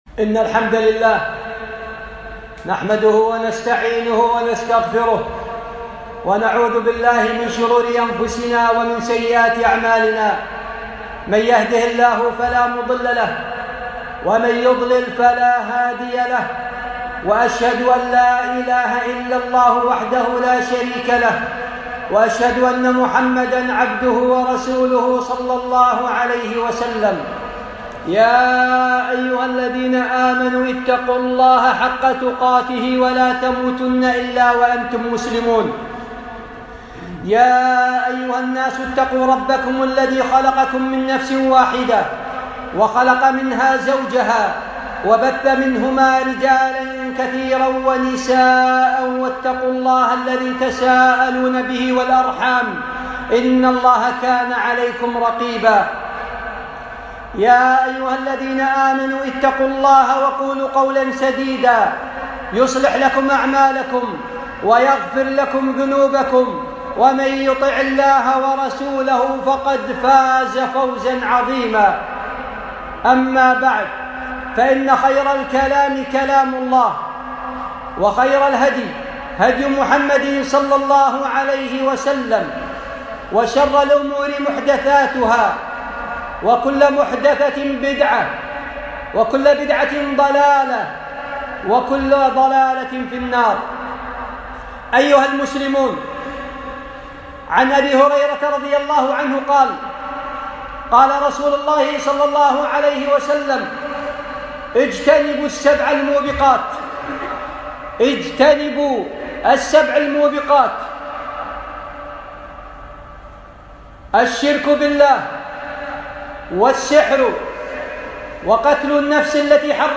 السبع الموبقات - خطبة